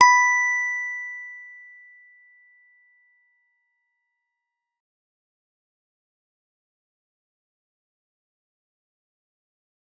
G_Musicbox-B5-f.wav